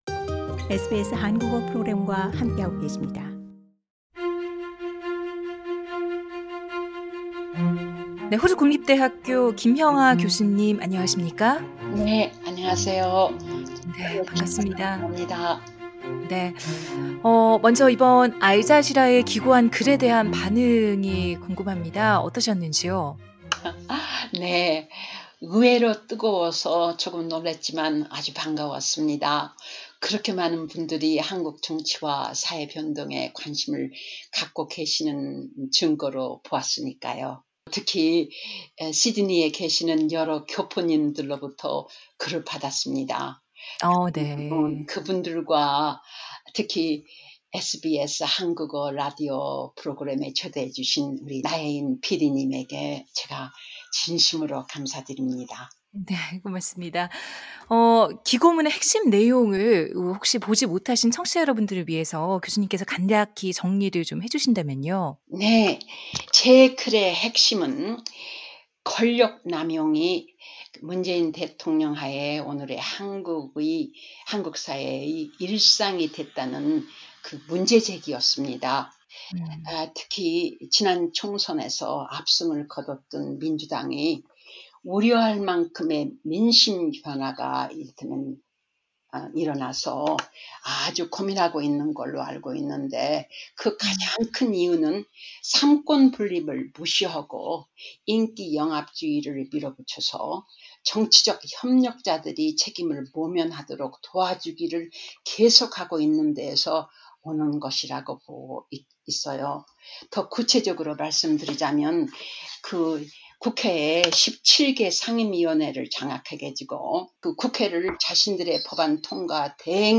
[대담]